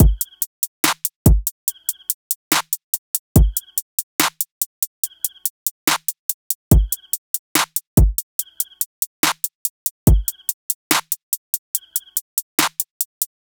SOUTHSIDE_beat_loop_mafia_full_01_143.wav